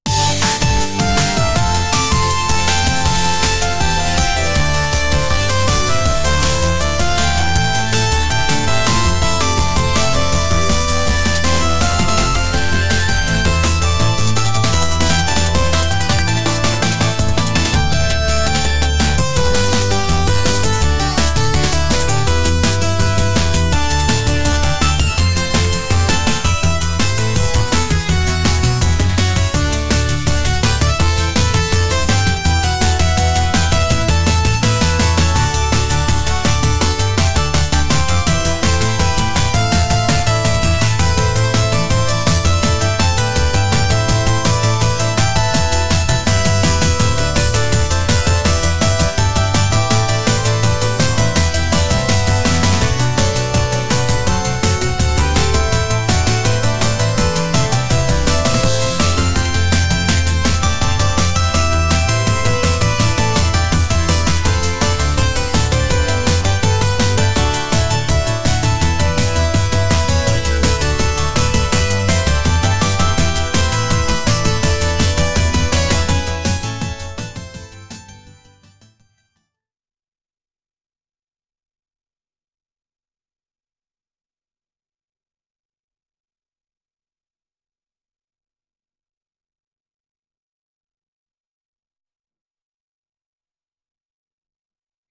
music
Christmas synths_1.wav